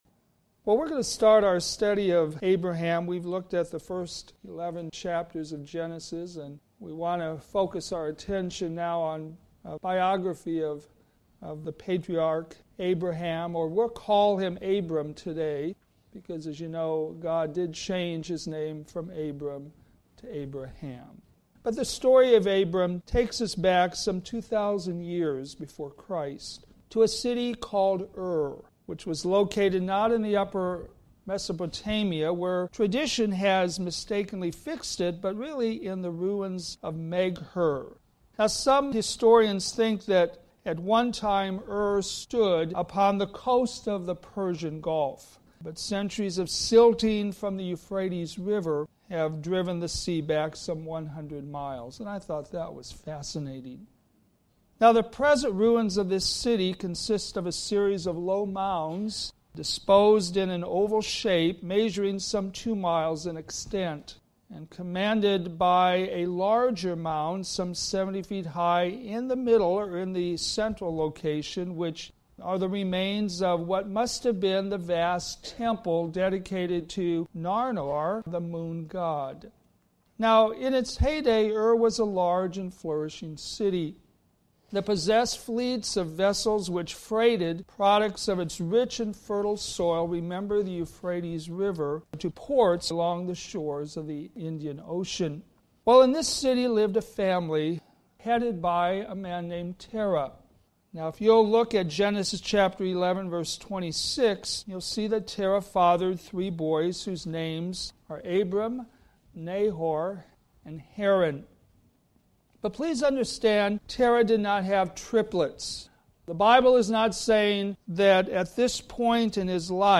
All Sermons - Westside Baptist Church
All sermons available in mp3 format